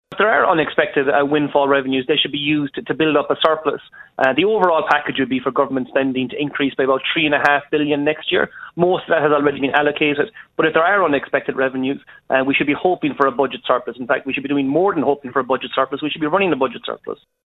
Council Chairman Seamus Coffey says the scope for any extras is limited, and we should be building buffers to deal with future shocks like Brexit.